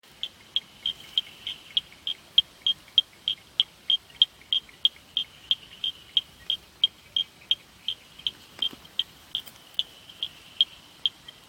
Kadalar Bush Frog Scientific Name: Raorchestes Kadalarensis